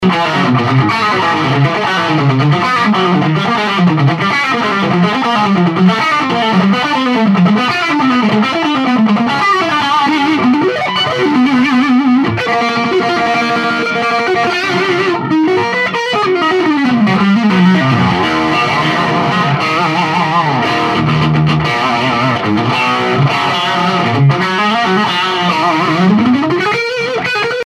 A strat,and used my PC sound card to record with,just a SM 58.